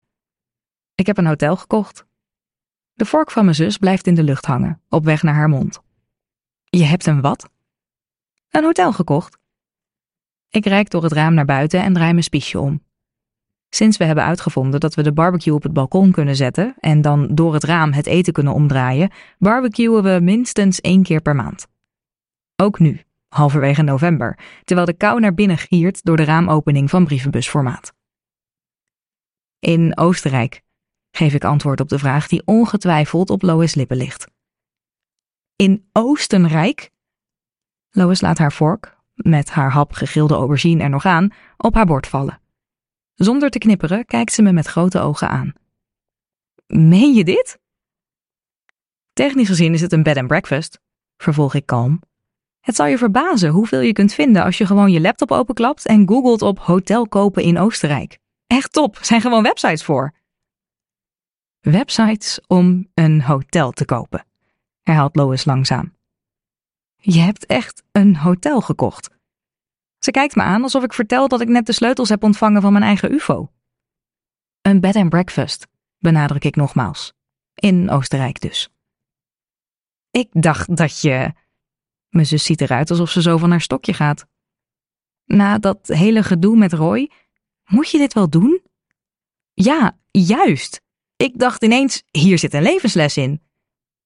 Hoe moeilijk kan het zijn luisterboek | Ambo|Anthos Uitgevers